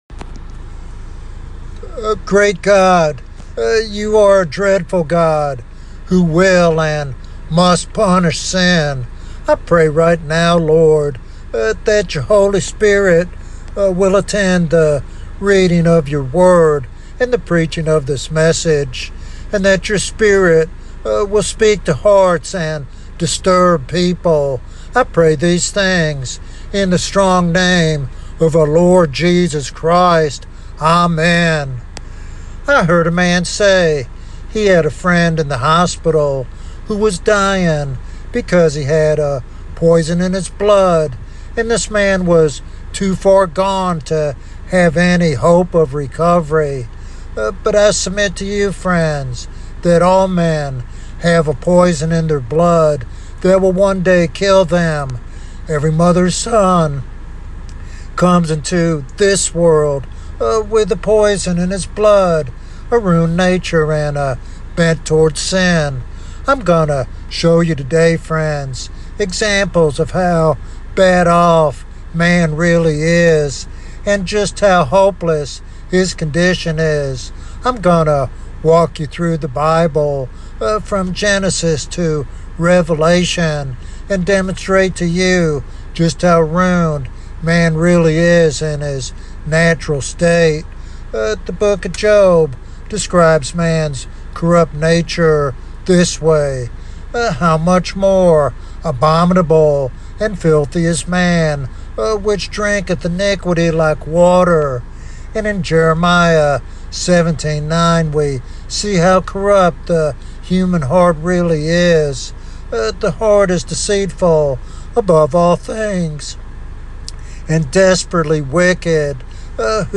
This sermon challenges believers and unbelievers alike to confront the reality of sin and embrace the hope found in Christ alone.